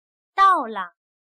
到了/dàole/Llegar